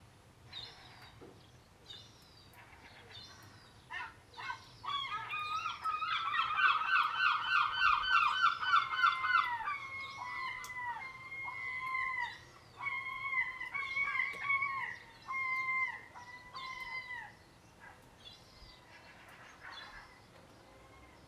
After 16 months living by the sea I am still surprised by the occasional loud seagull - I recorded this from inside the kitchen.